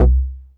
33SYN.BASS.wav